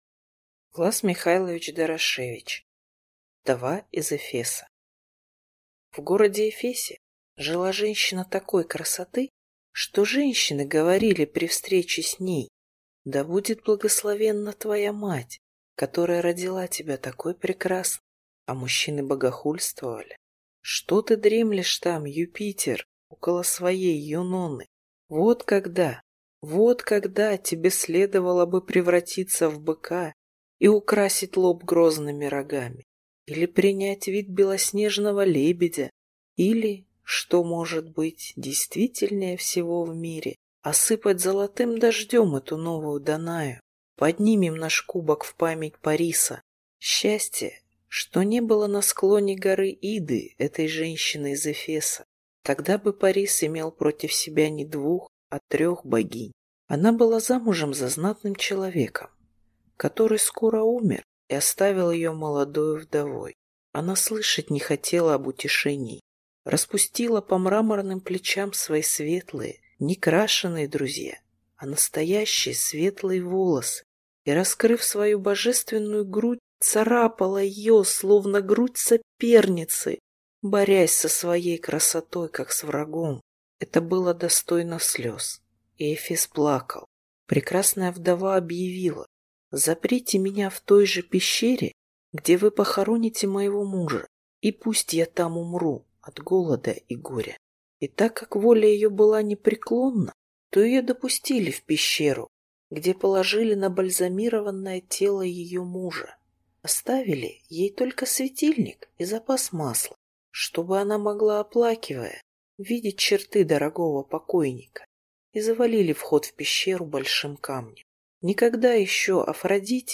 Аудиокнига Вдова из Эфеса | Библиотека аудиокниг
Прослушать и бесплатно скачать фрагмент аудиокниги